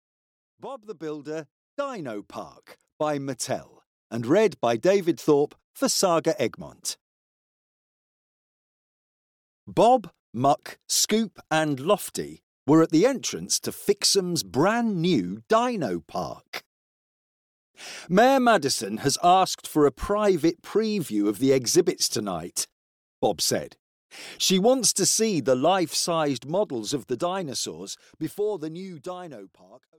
Bob the Builder: Dino Park (EN) audiokniha
Ukázka z knihy